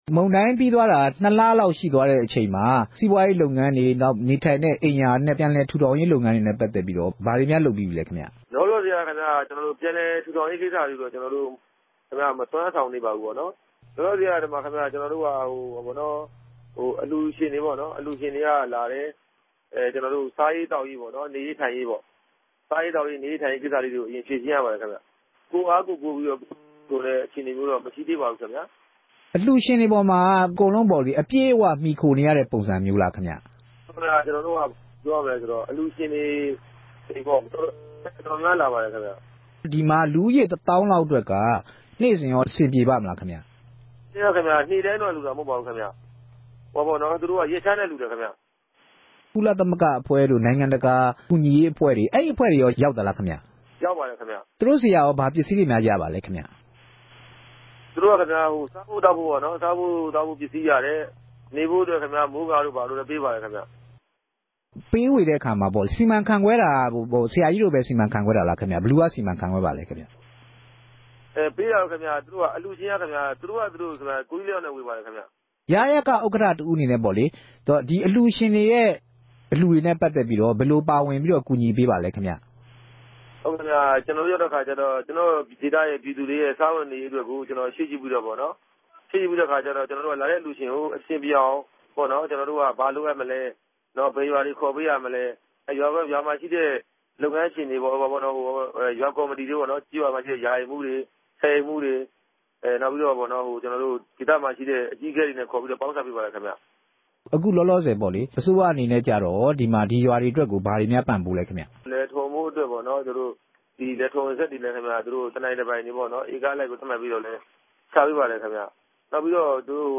ရယကဥက္ကှိူံြင့် ဆက်သြယ်မေးူမန်းခဵက်။်